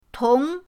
tong2.mp3